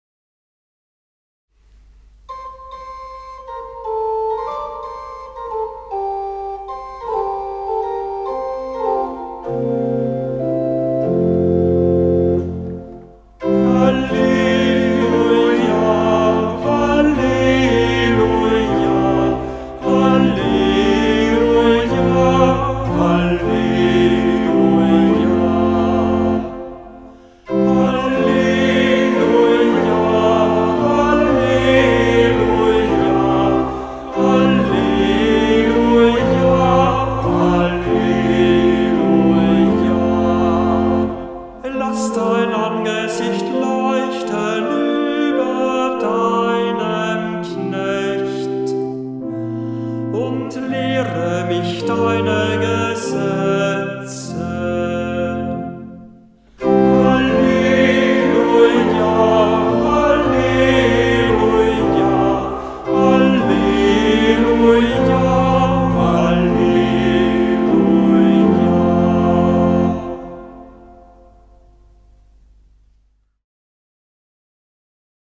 Ruf vor dem Evangelium - September 2025